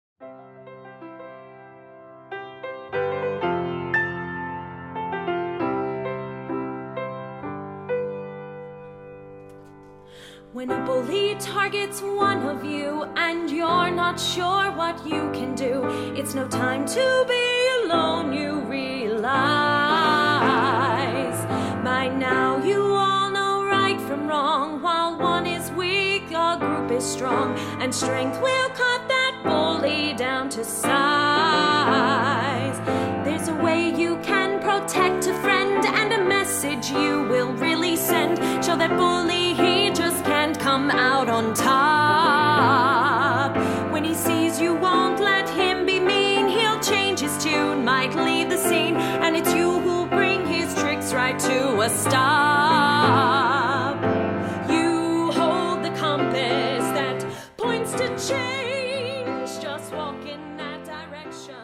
The music was recorded at The Audio Workshop.